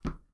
Footsteps
step4.wav